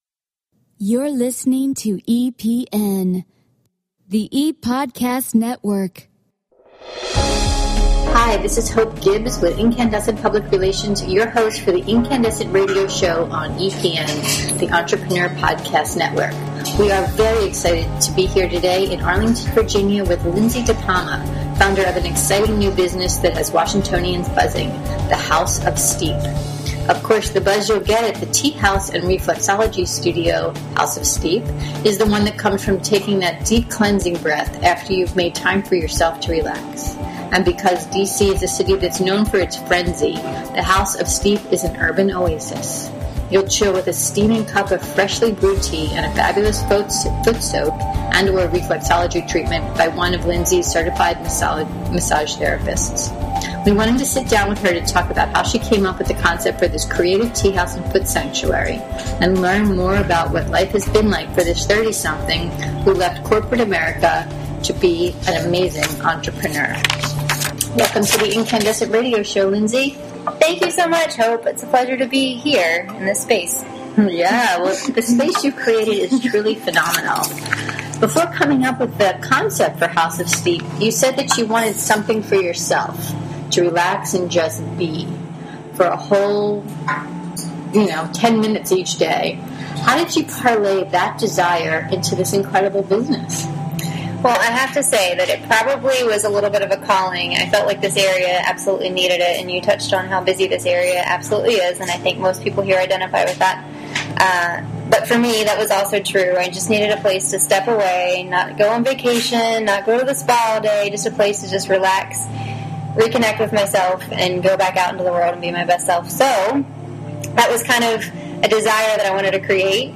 in her lovely, relaxing shop